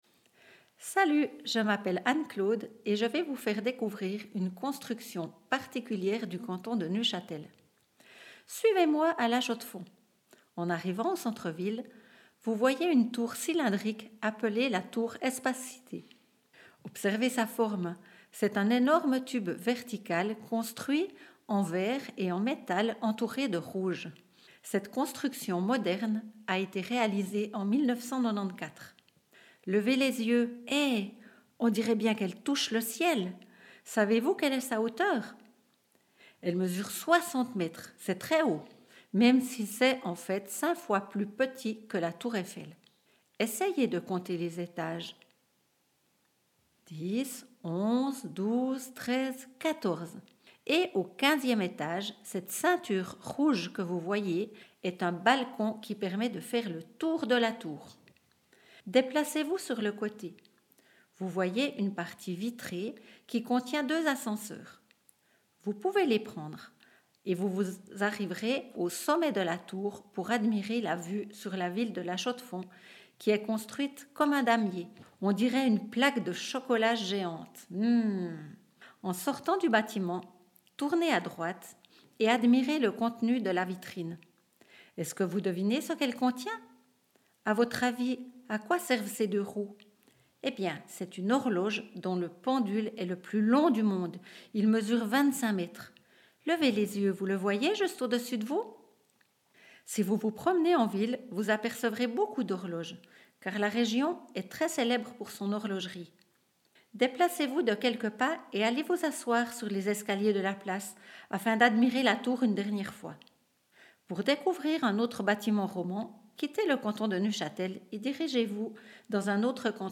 AUDIOGUIDE LA TOUR ESPACITÉ (RNS3)